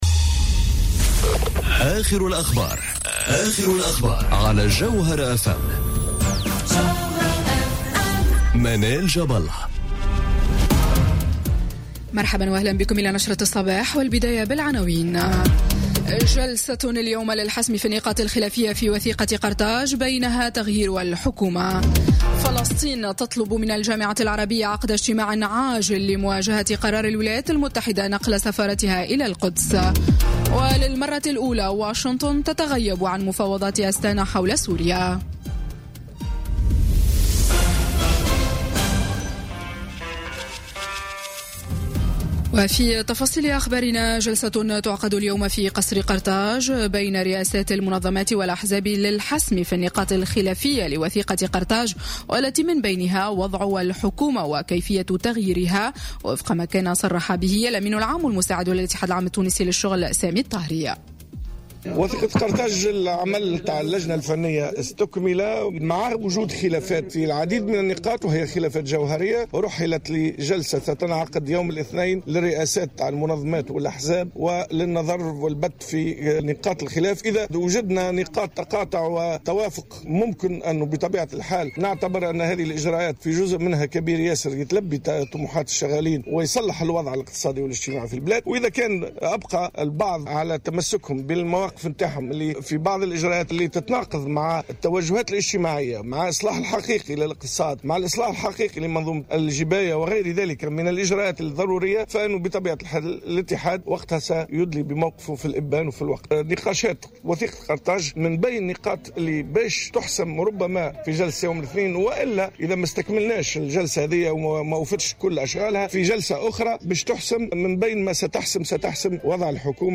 نشرة أخبار السابعة صباحا ليوم الإثنين 14 ماي 2018